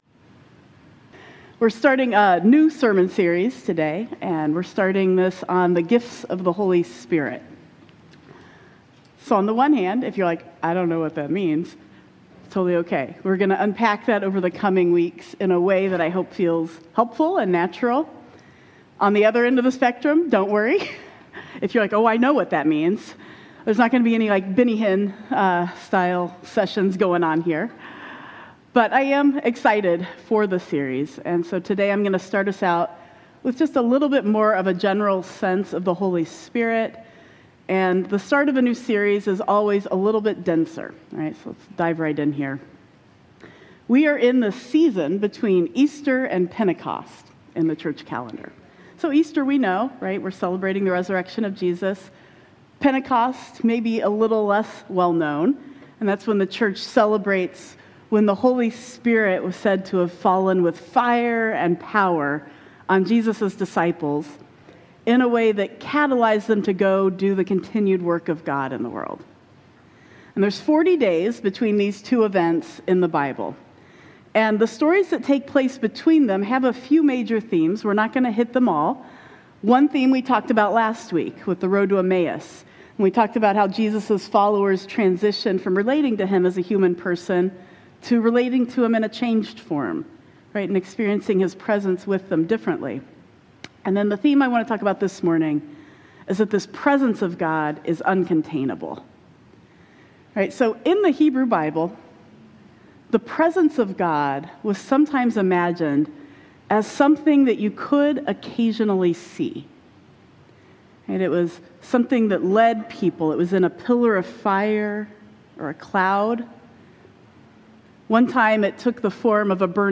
We’re starting a new sermon series this morning on the gifts of the Holy Spirit. We talk about how the stories between Easter and Pentecost point to a God whose Spirit is uncontained and moving in the world.